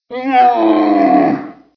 c_camel_slct.wav